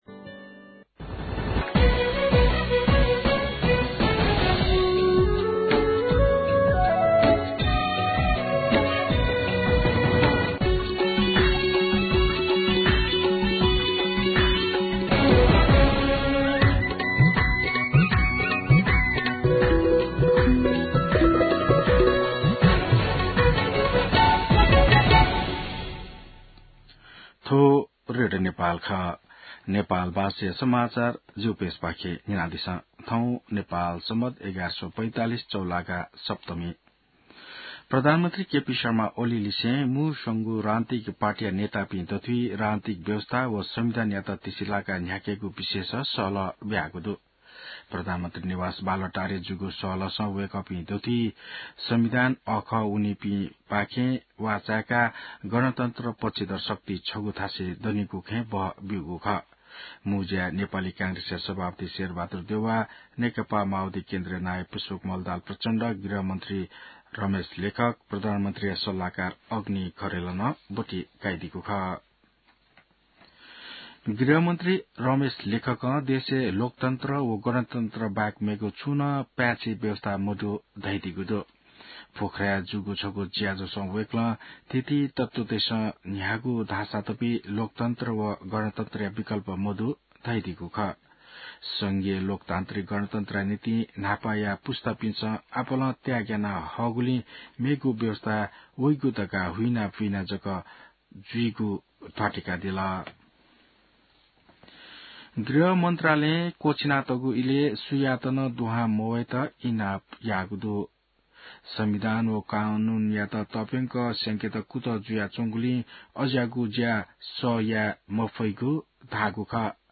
नेपाल भाषामा समाचार : ७ वैशाख , २०८२